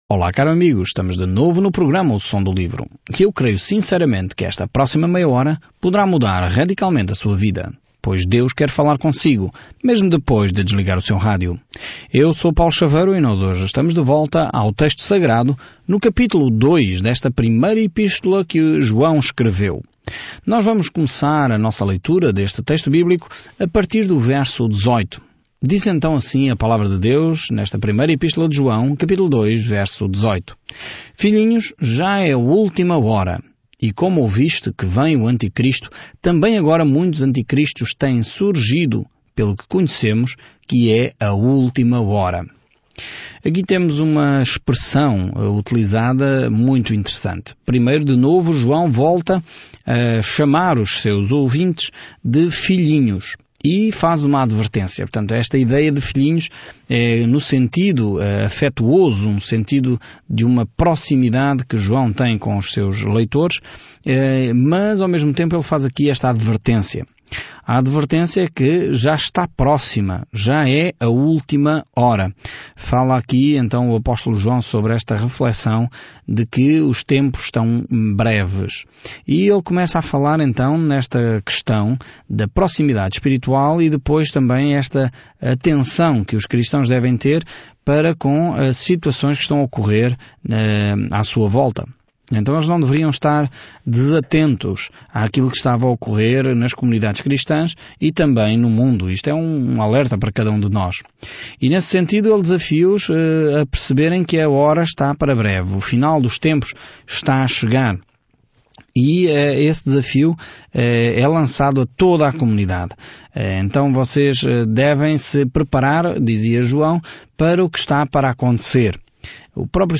Escritura 1 JOÃO 2:18-19 Dia 9 Iniciar este Plano Dia 11 Sobre este plano Não há meio-termo nesta primeira carta de João – ou escolhemos a luz ou as trevas, a verdade às mentiras, o amor ou o ódio; abraçamos um ou outro, assim como acreditamos ou negamos o Senhor Jesus Cristo. Viaje diariamente por 1 João enquanto ouve o estudo em áudio e lê versículos selecionados da palavra de Deus.